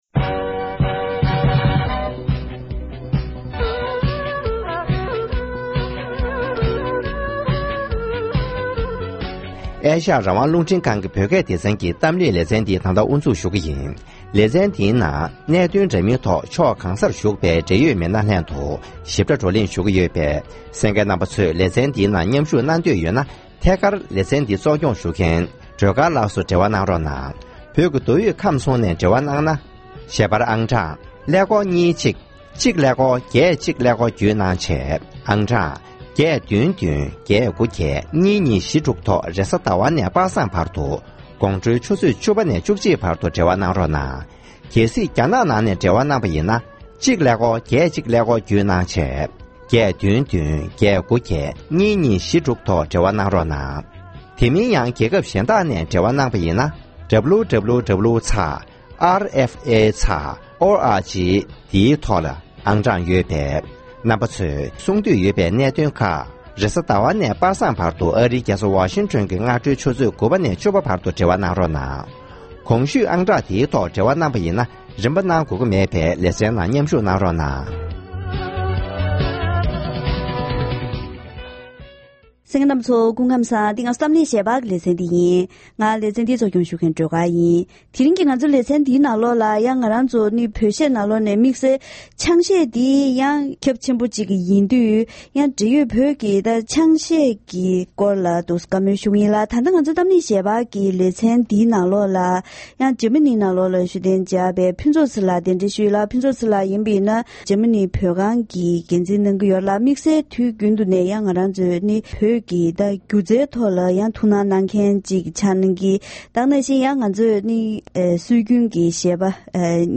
༄༅། །ཐེངས་འདིའི་གཏམ་གླེང་ཞལ་པར་ལེ་ཚན་ནང་བོད་ཀྱི་ལོ་གསར་དང་སྐབས་བསྟུན་ཞུས་ཏེ། བོད་ཀྱི་ཡུལ་ལུང་ཁག་ནང་དམངས་ཁྲོད་དུ་དར་བའི་བོད་ཀྱི་ཆང་གཞས་སྐོར་ལ་འབྲེལ་ཡོད་མི་སྣ་ཁག་ཅིག་ལྷན་བཀའ་མོལ་ཞུས་པ་ཞིག་གསན་རོགས་གནང་།